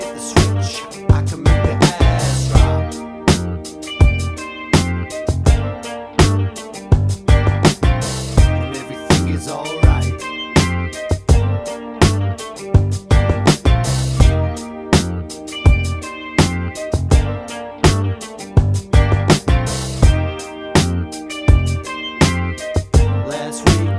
no Backing Vocals R'n'B / Hip Hop 4:21 Buy £1.50